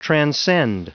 Prononciation du mot transcend en anglais (fichier audio)
Prononciation du mot : transcend